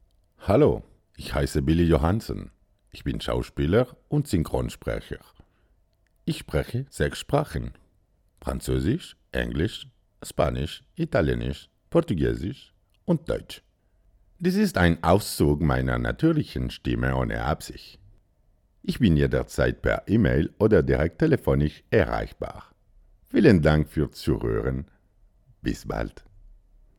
Voix off
40 - 80 ans - Baryton-basse